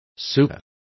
Also find out how colector is pronounced correctly.